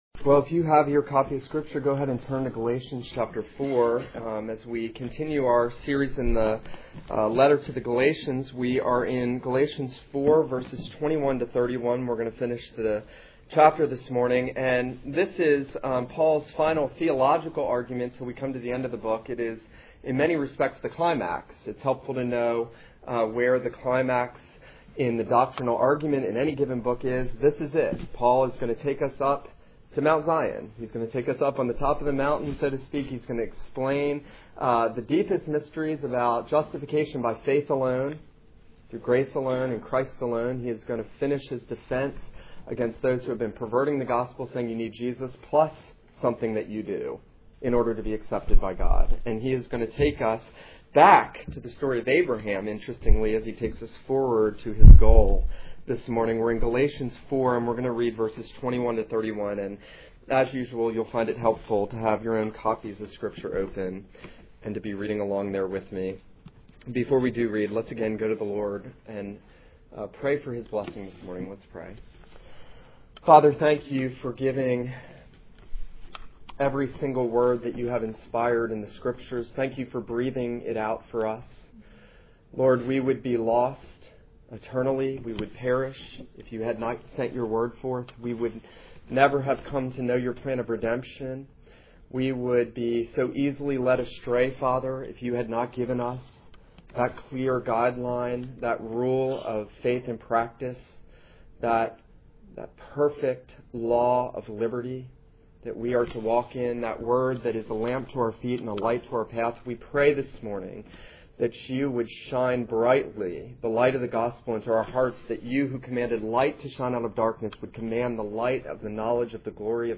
This is a sermon on Galatians 4:21-31.